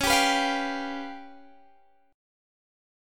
C#7b5 Chord
Listen to C#7b5 strummed